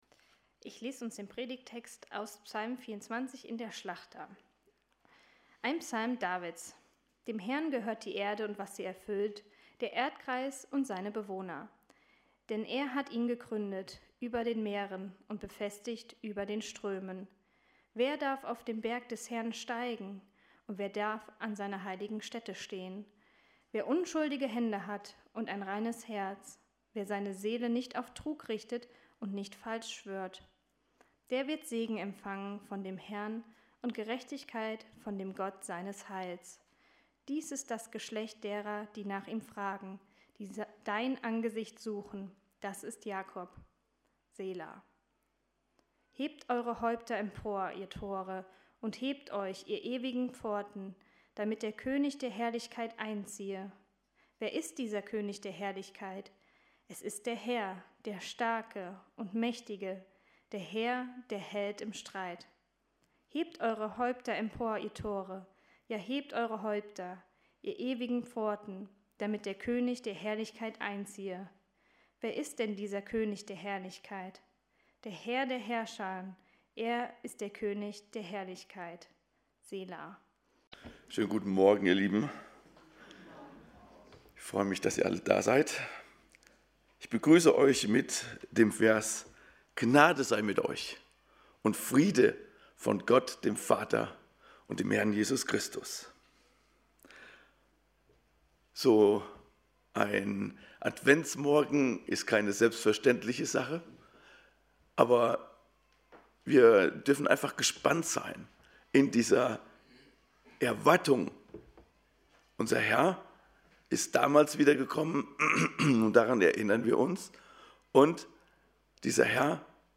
Predigt zum 2. Advent ~ Predigt Podcast Evangelische Gemeinschaft Kredenbach Podcast
predigt-zum-2-advent.mp3